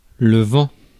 Ääntäminen
Synonyymit pet paroles en l'air autan Ääntäminen France: IPA: [vɑ̃] Haettu sana löytyi näillä lähdekielillä: ranska Käännös Konteksti Ääninäyte Substantiivit 1. hot air idiomaattinen 2.